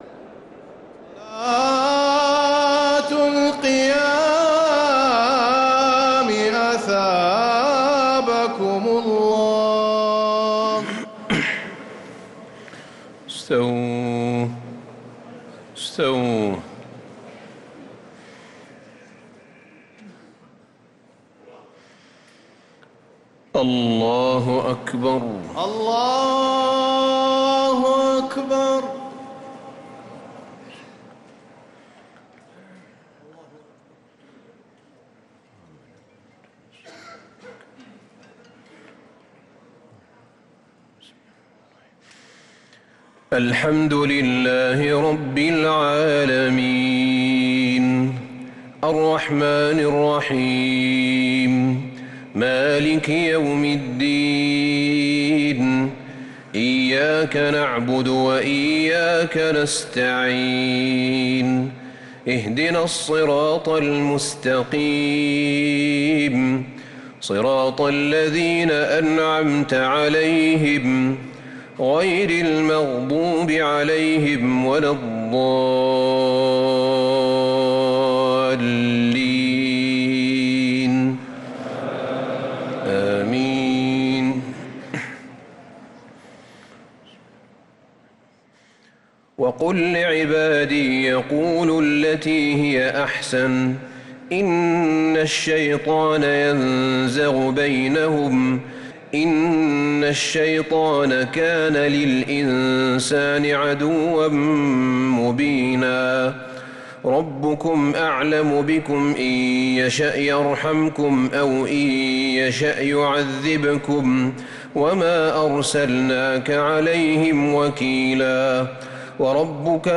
تراويح ليلة 20 رمضان 1446هـ من سورتي الإسراء (53-111) و الكهف (1-26) | Taraweeh 20th night Ramadan1446H Surah Al-Israa and Al-Kahf > تراويح الحرم النبوي عام 1446 🕌 > التراويح - تلاوات الحرمين